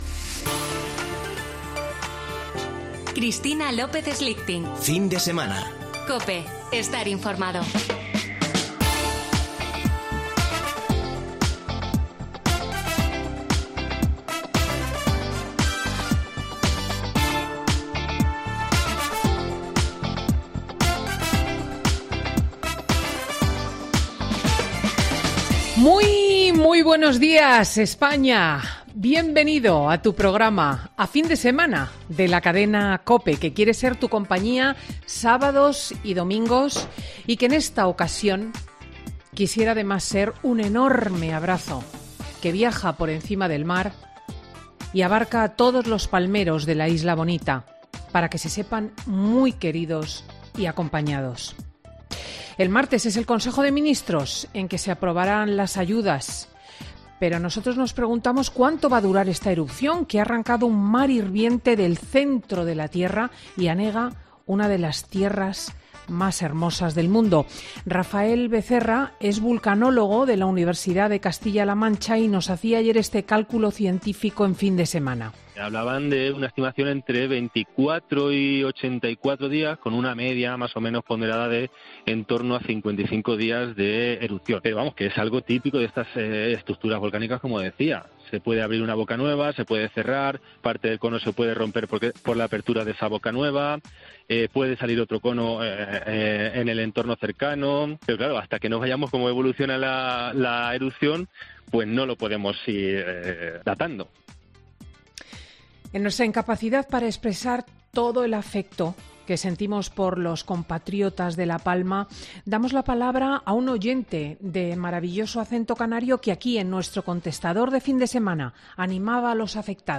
La presentadora de 'Fin de Semana' reflexiona sobre las elecciones en Alemania y el adiós de Angela Merkel
En nuestra incapacidad para expresar todo el afecto que sentimos por los compatriotas de La Palma, damos la palabra a un oyente de maravilloso acento canario que, aquí, en nuestro contestador, animaba a los afectados.